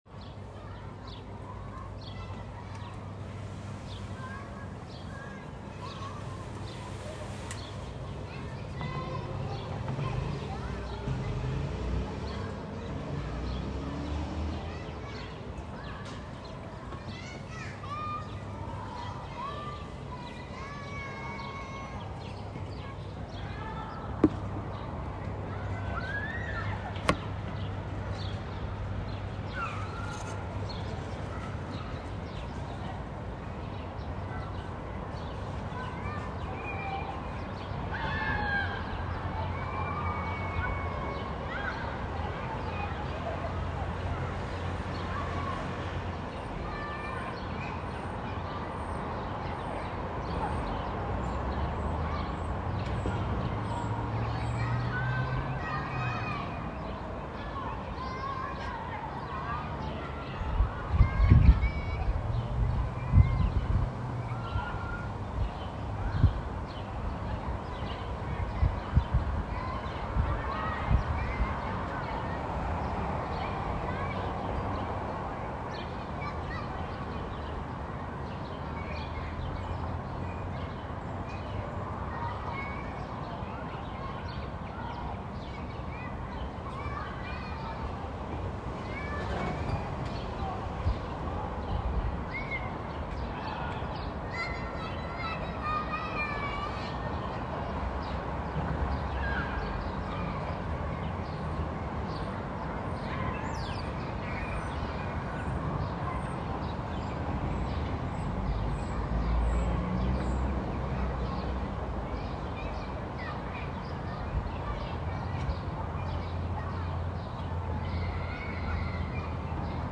Performed at the Cary Hall at the DiMenna Center for Classical Music on January 17, 2019.
soprano
piano.